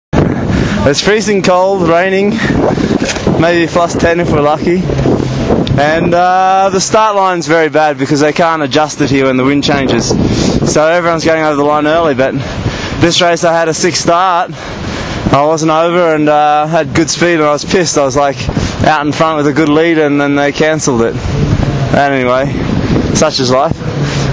More interviews… – Formula Windsurfing